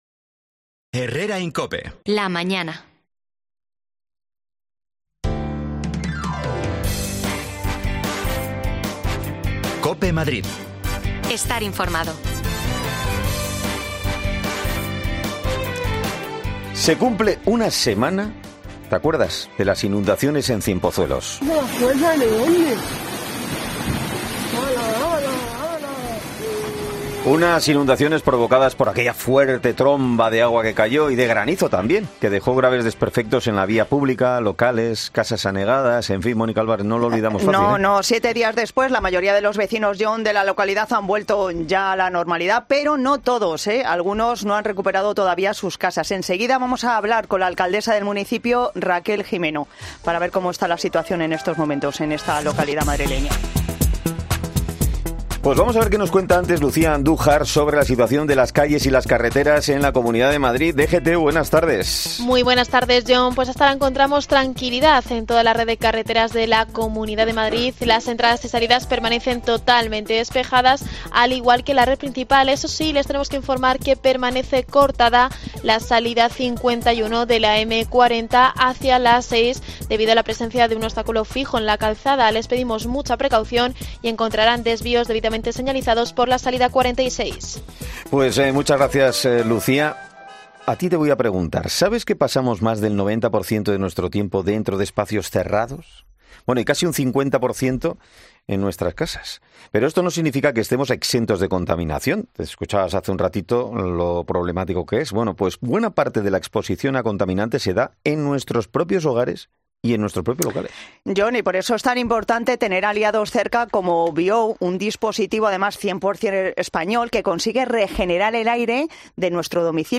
Se cumple una semana de las inundaciones de Ciempozuelos que dejó anegadas calles, garajes y casas. La alcaldesa Raquel Jimeno nos hace balance una semana después
Las desconexiones locales de Madrid son espacios de 10 minutos de duración que se emiten en COPE , de lunes a viernes.